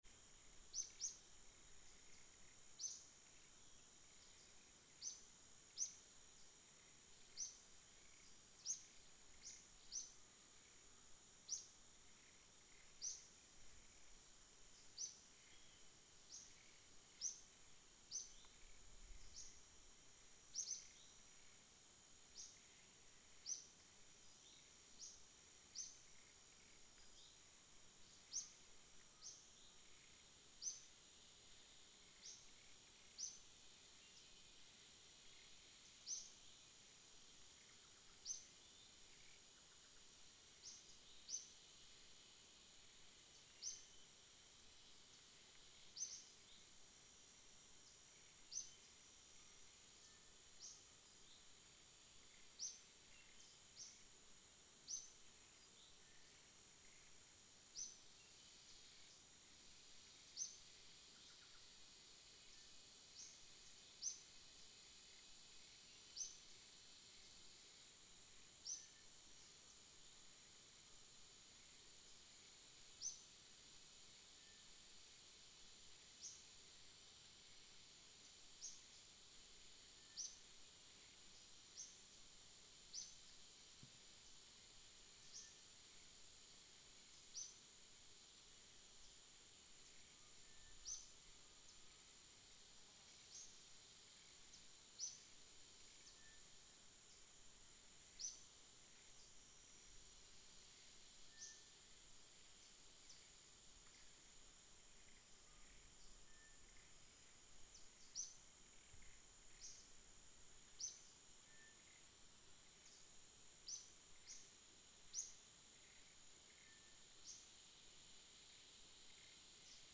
Jungle-Atmosphere-Afternoon.mp3